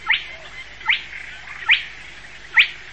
Voltolino – Giraldìna
Porzana porzana
Voce
Voltino_Porzana_porzana.mp3